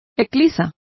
Complete with pronunciation of the translation of fishplate.